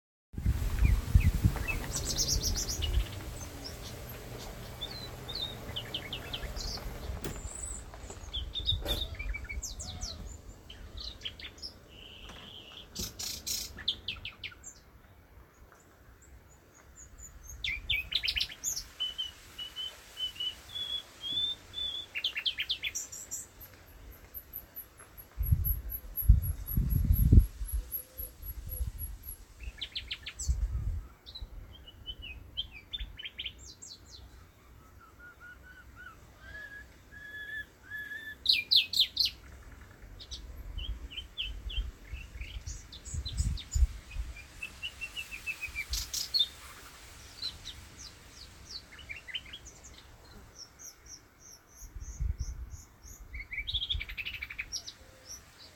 برنامه تالاب قنبرآباد به مناسبت روز جهانی پرندگان مهاجر
Thrush Nightingale (Luscinia luscinia)
بلبل خالدار (صدا)